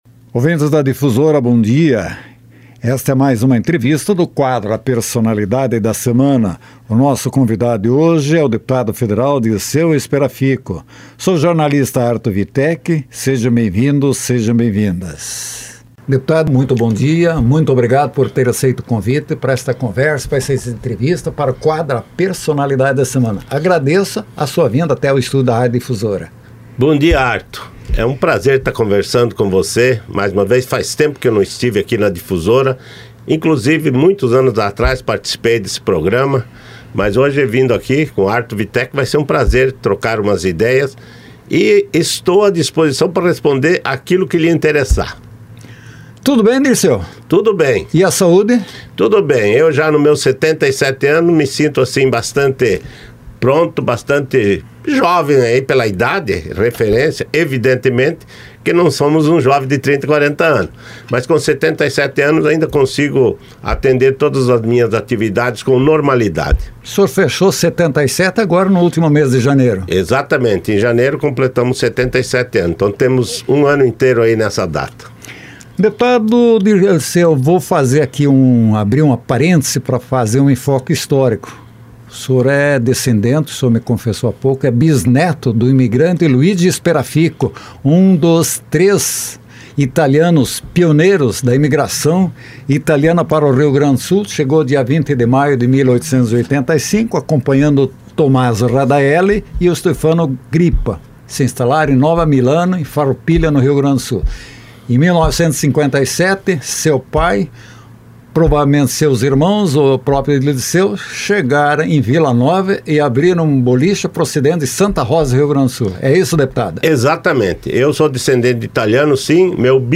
Deputado federal Dilceu Sperafico foi o nosso entrevistado em A Personalidade da Semana